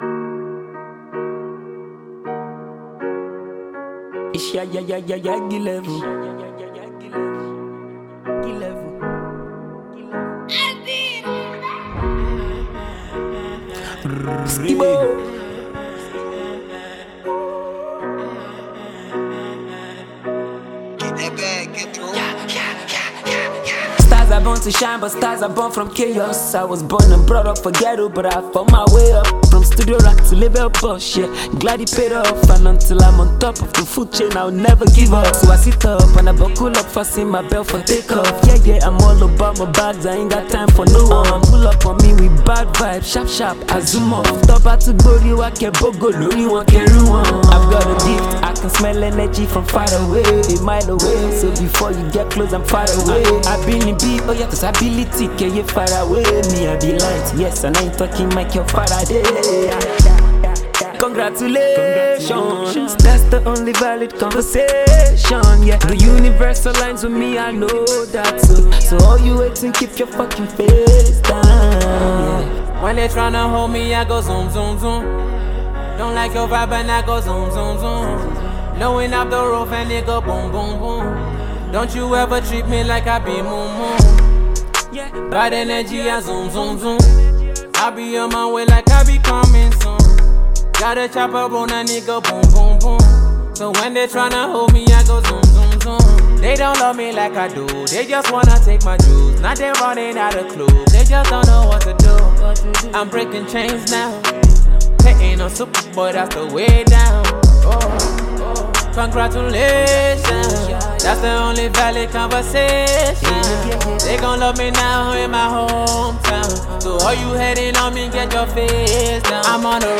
usual Yoruba vibes
slow tempo tune
Its highly energetic and entertaining